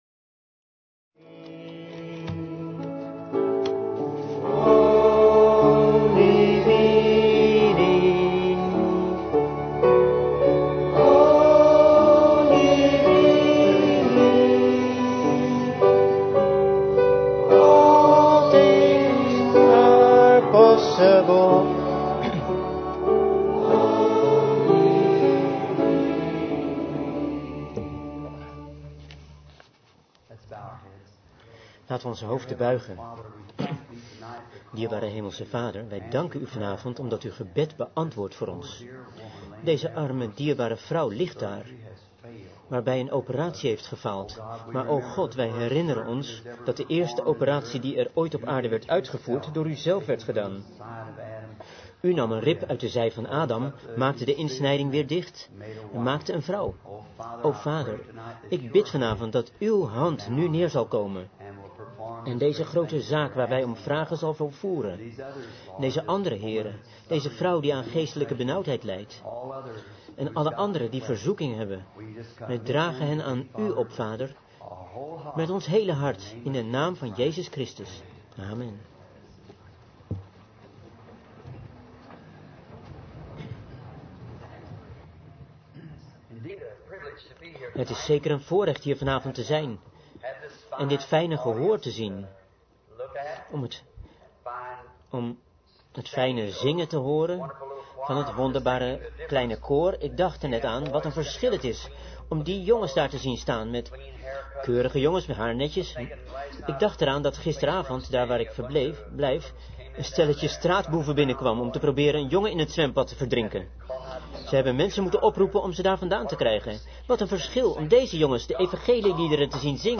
Prediking
Locatie Orange Show Auditorium San Bernardino , CA